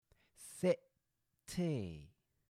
sip tea – /sɪʔp.tiː/